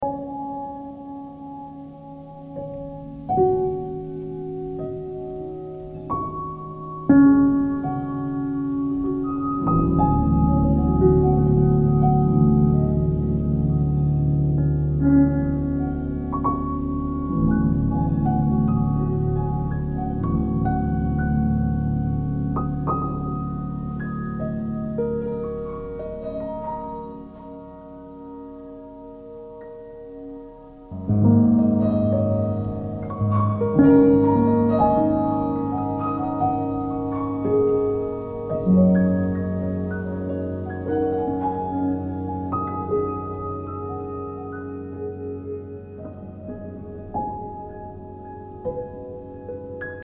Ambient, Drone >